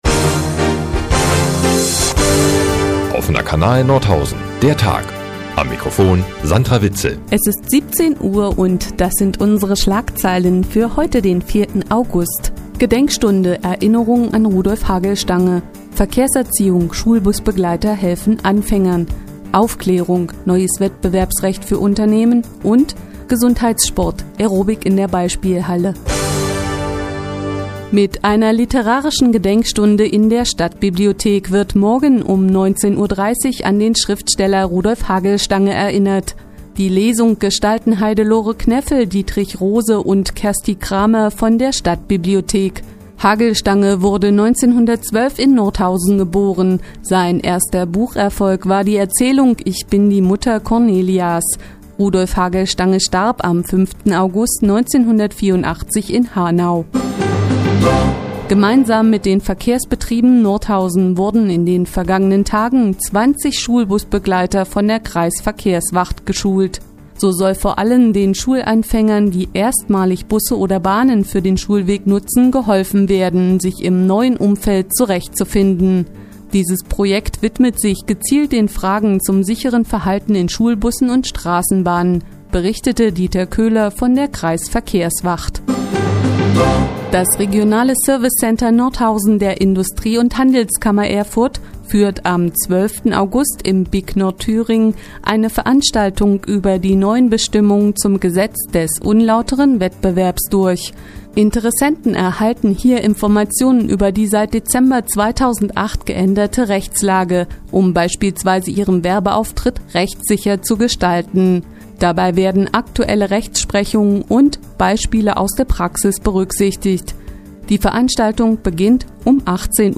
Die tägliche Nachrichtensendung des OKN ist nun auch in der nnz zu hören. Heute gibt es Informationen zur Aerobic-Night, Schulbusbegleitern, dem Schriftsteller Rudolf Hagelstange und einem Lehrgang der Industrie- und Handelskammer Erfurt.